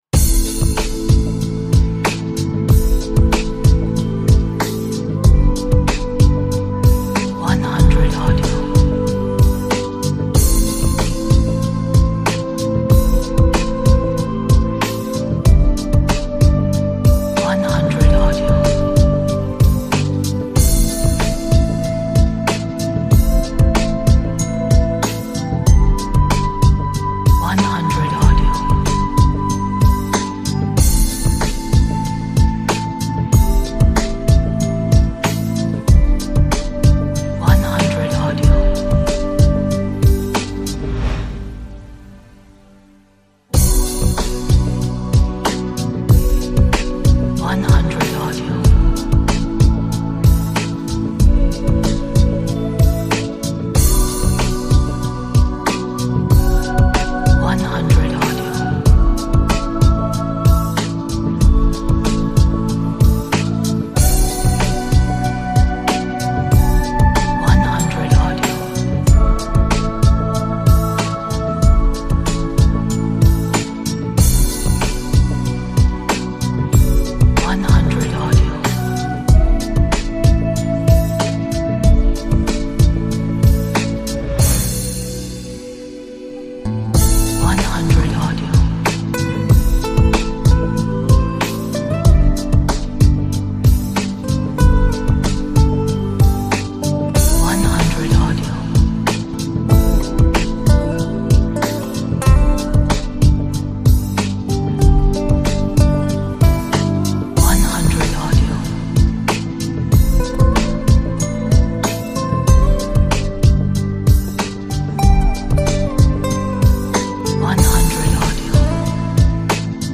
平稳大气的鼓点，加上空灵的音乐 适合走秀模特，时装舞台秀等场合